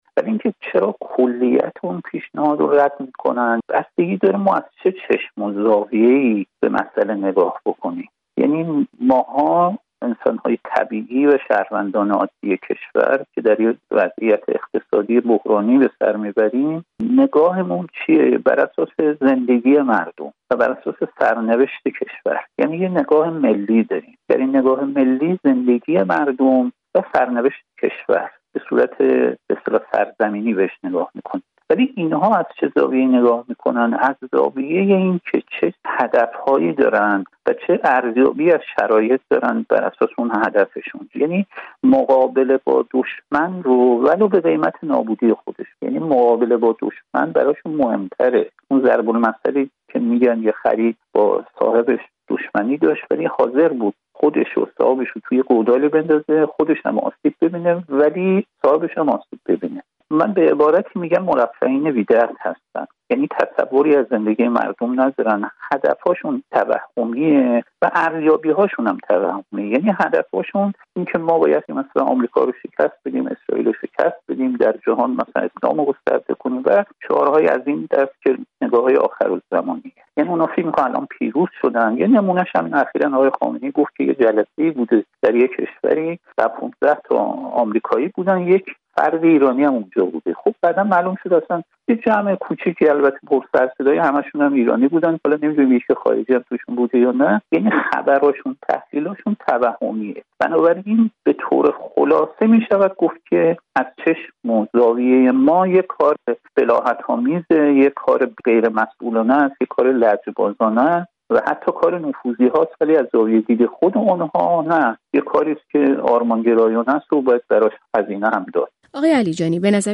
تحلیل‌گر مسائل ایران در فرانسه